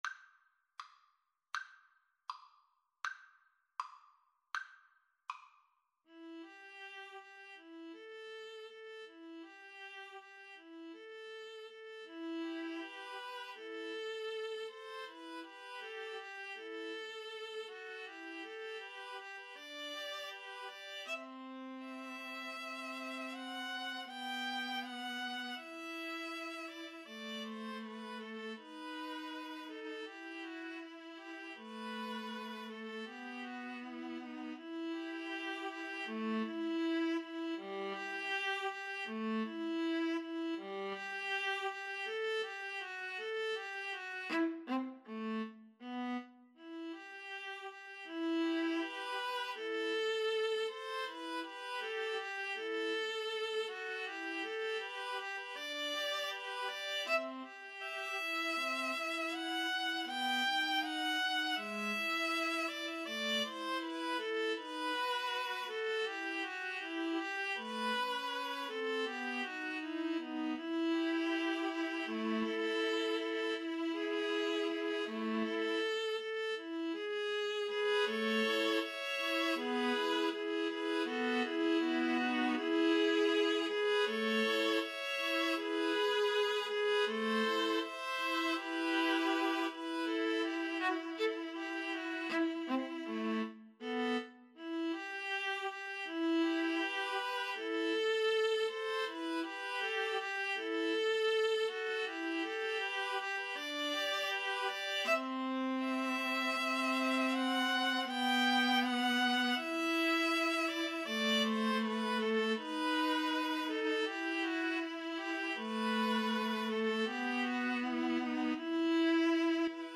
E minor (Sounding Pitch) (View more E minor Music for Viola Trio )
~ = 100 Andante
Classical (View more Classical Viola Trio Music)